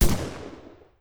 New gunshot SFX
shotgunGunshot.wav